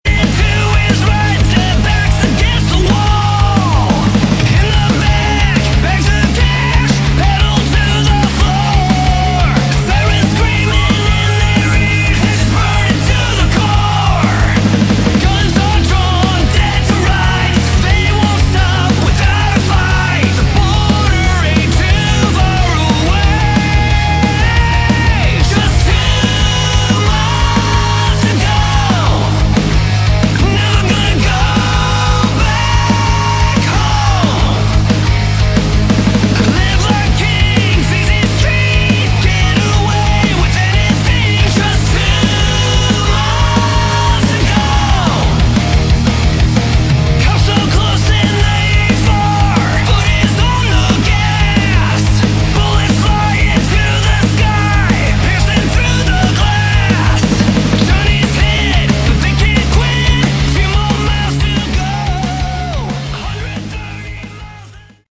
Category: AOR / Melodic Rock
Guitars, Bass, Drums, Keys